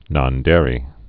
(nŏn-dârē)